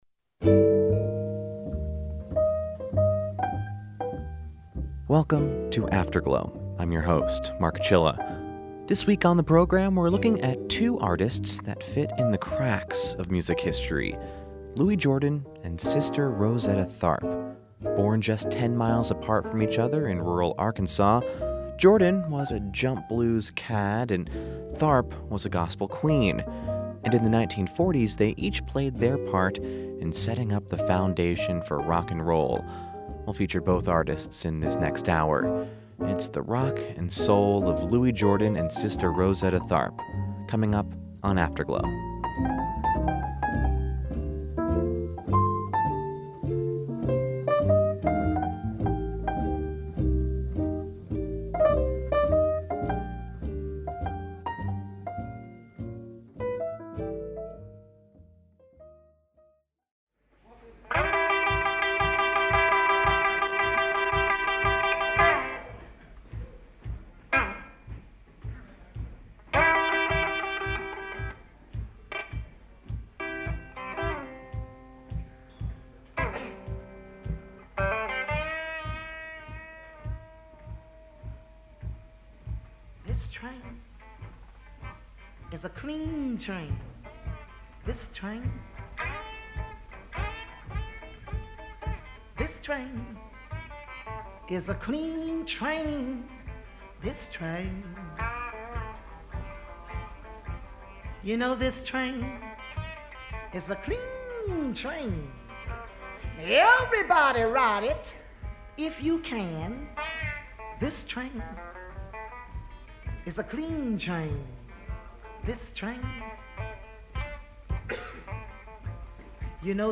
jump blues